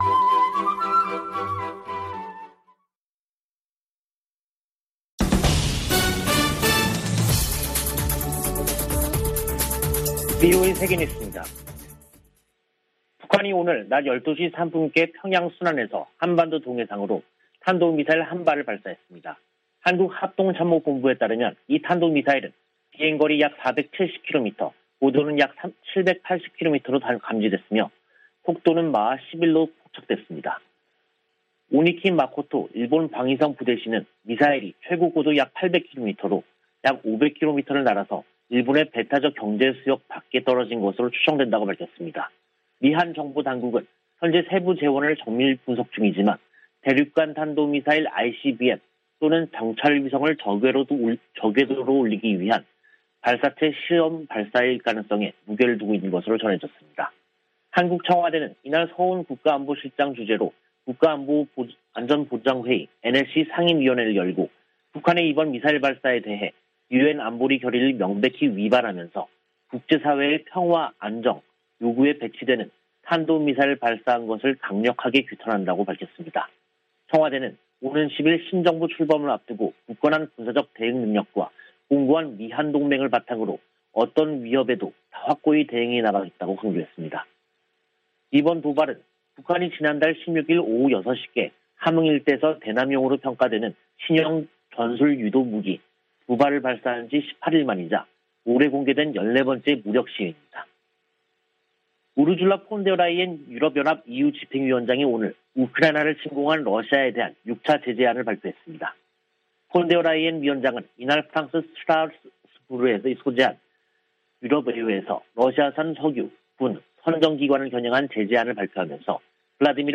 VOA 한국어 간판 뉴스 프로그램 '뉴스 투데이', 2022년 5월 4일 2부 방송입니다. 북한이 한국 새 정부 출범을 앞두고 또 다시 탄도미사일을 발사했습니다. 미국은 북한의 대륙간탄도미사일(ICBM) 도발에 대한 새 유엔 안보리 결의를 위해 논의하고 있다고 밝혔습니다. 세계 여러 나라가 안보리 대북 제재 조치 등을 즉각 수용할 수 있는 법적 체계를 갖추지 못했다는 자금세탁방지기구의 평가가 나왔습니다.